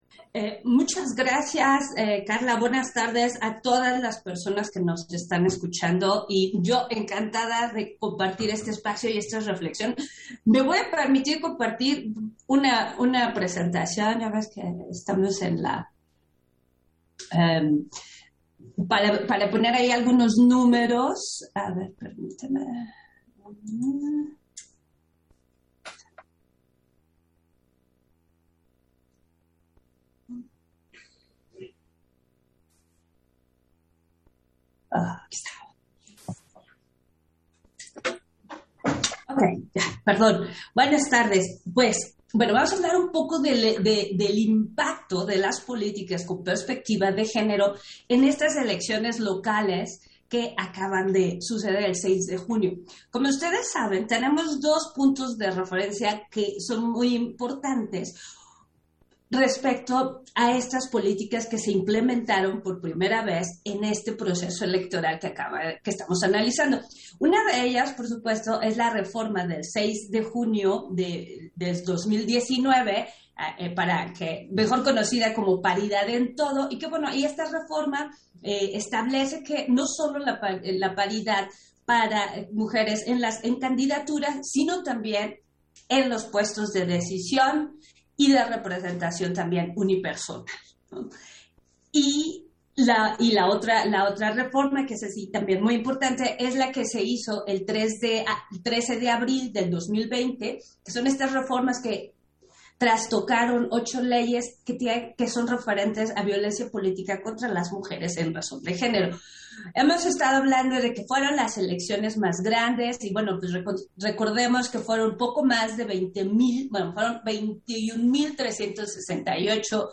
Intervención de Norma de la Cruz, en la mesa, Elecciones Locales, en el marco del Foro: Encuestas y elecciones 2021